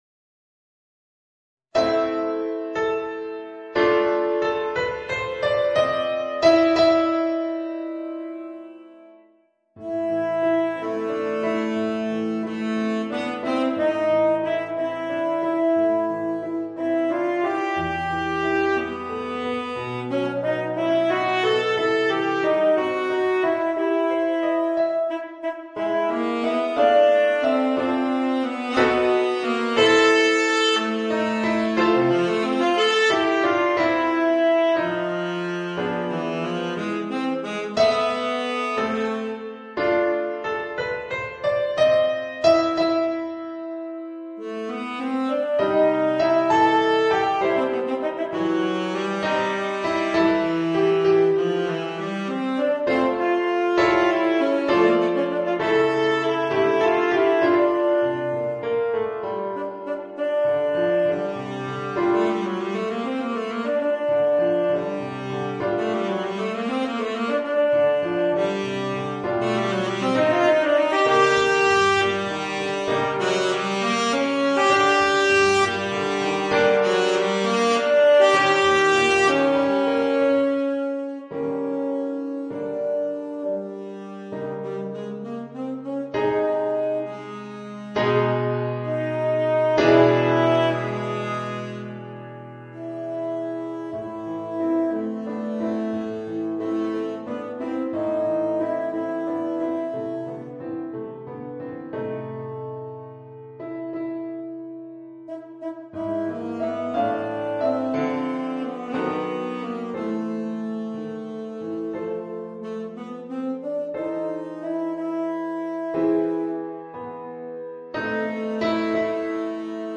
Saxophone ténor & piano